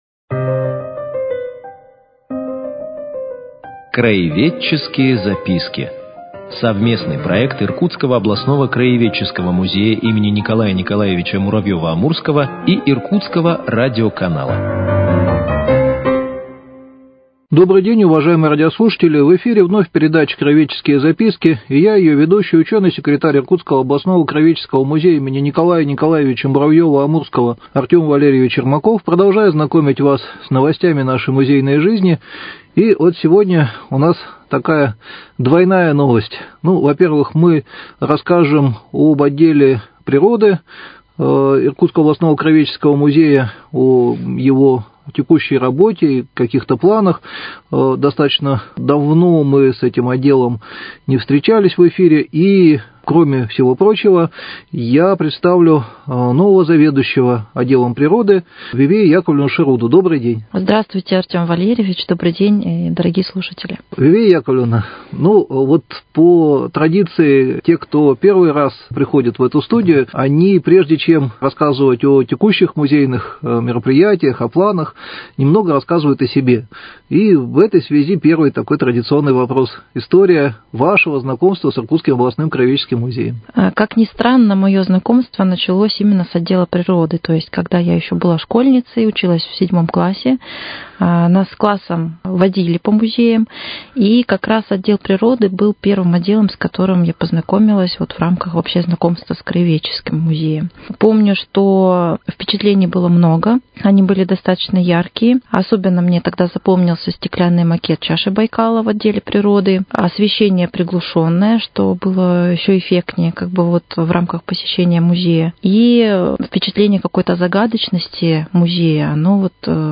Мы предлагаем вниманию слушателей цикл передач – совместный проект Иркутского радиоканала и Иркутского областного краеведческого музея.